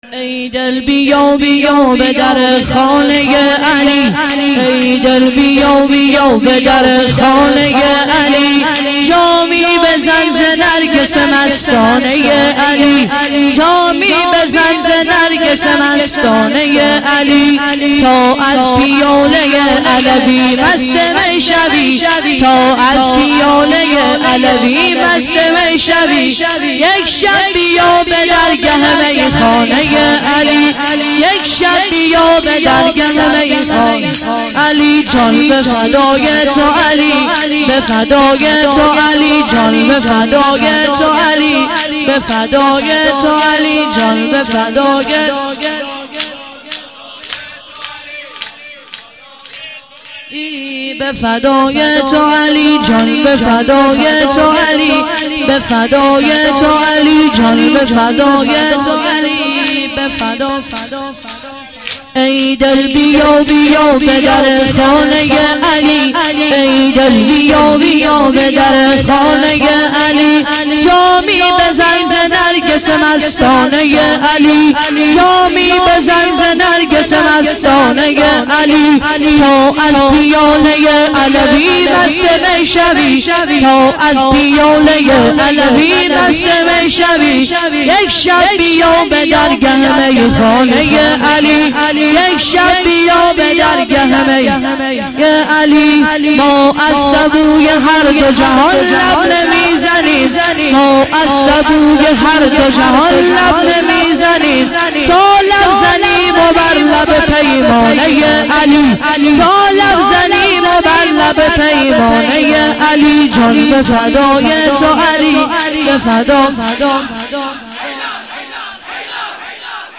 ای دل بیا بیا به در خانه ی علی - مداح